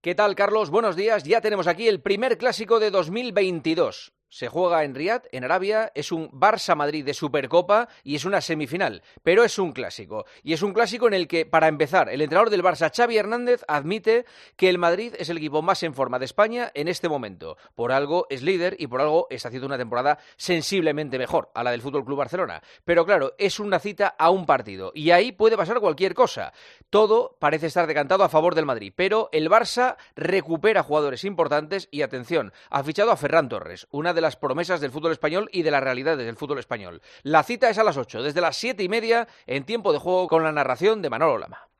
El comentario de Juanma Castaño